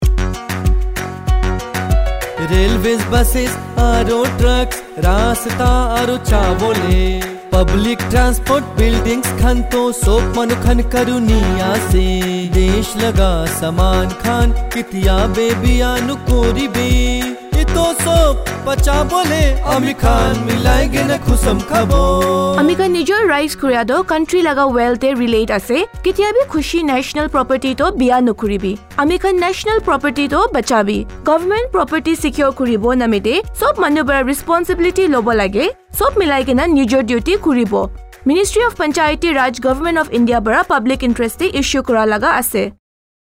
176 Fundamental Duty 9th Fundamental Duty Safeguard public property Radio Jingle Nagamese